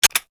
12 Gauge Shotgun Pistol shot
s_handgun_fast_reload_ef11.wav